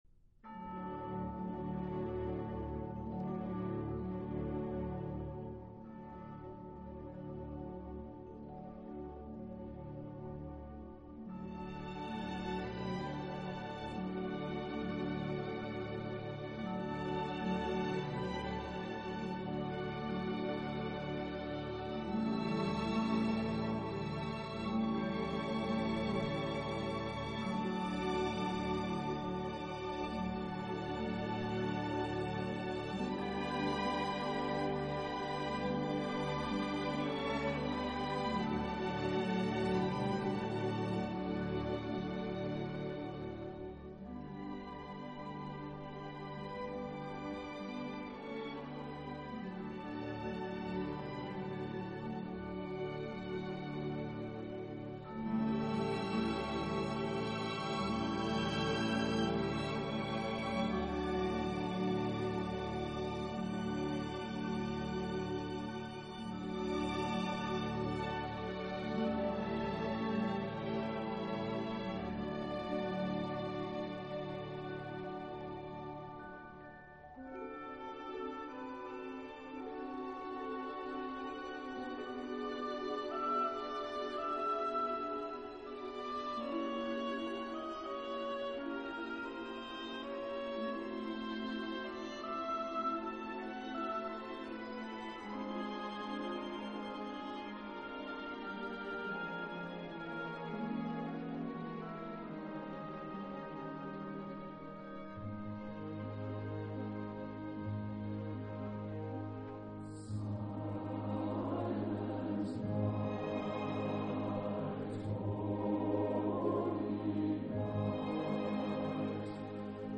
类 别：管弦乐